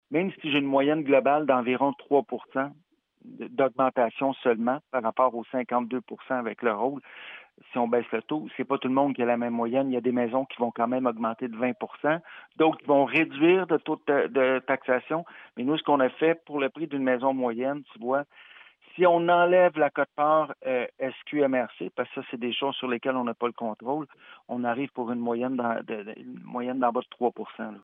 Le maire de Farnham, Patrick Melchior.